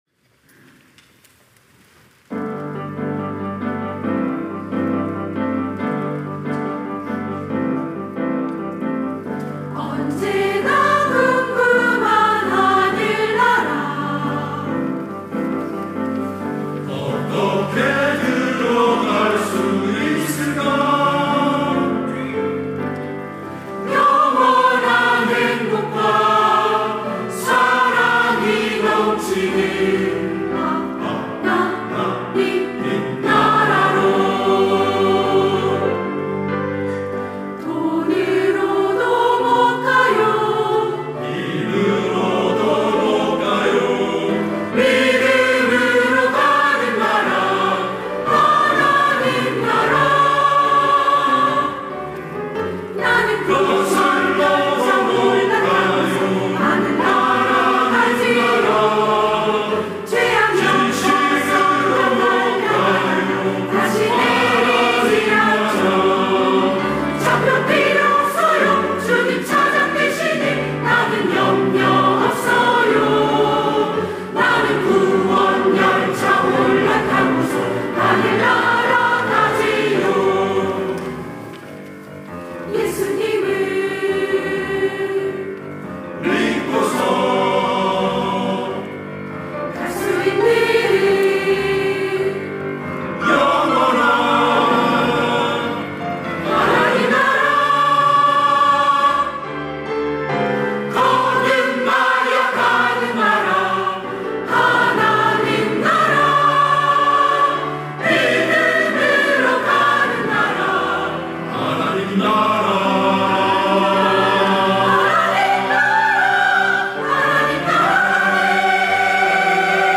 시온(주일1부) - 하나님 나라
찬양대 시온